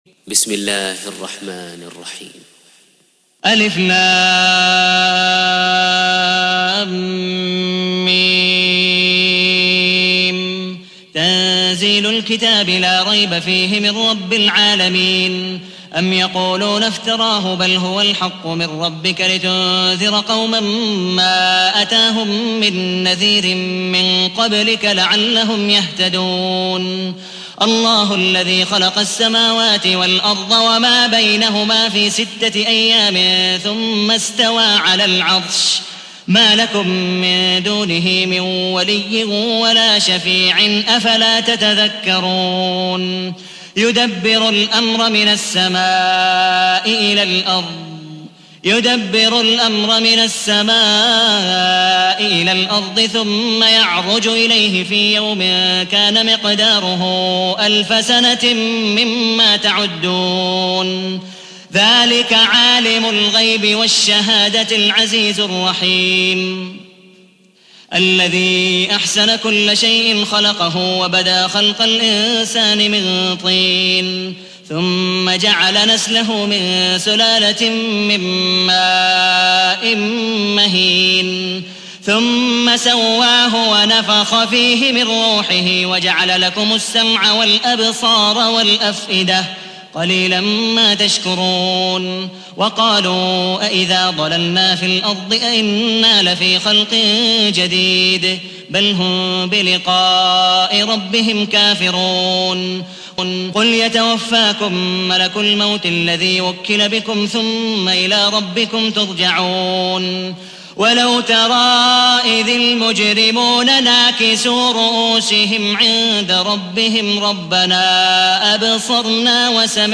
تحميل : 32. سورة السجدة / القارئ عبد الودود مقبول حنيف / القرآن الكريم / موقع يا حسين